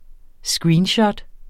Udtale [ ˈsgɹiːnˌɕʌd ]